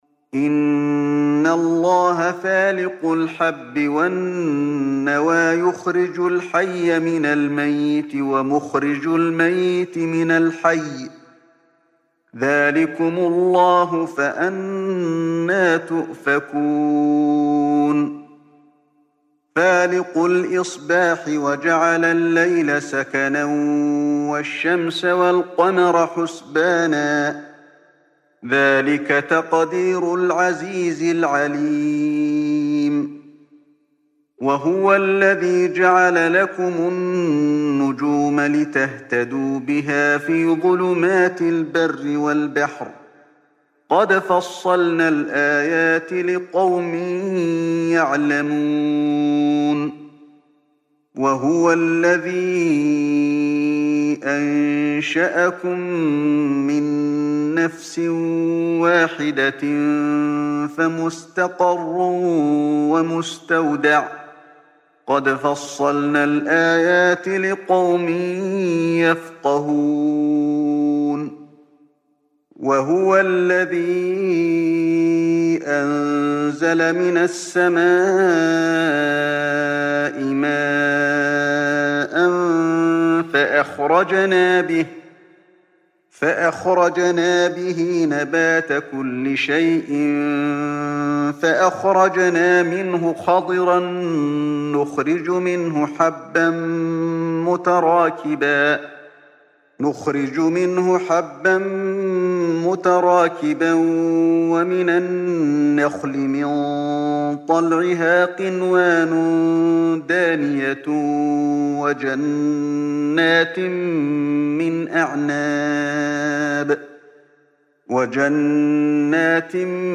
Чтение аятов 95-103 суры «аль-Ан’ам» шейхом ’Али бин ’Абд ар-Рахманом аль-Хузейфи, да хранит его Аллах.